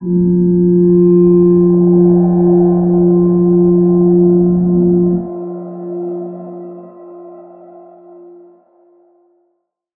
G_Crystal-F4-f.wav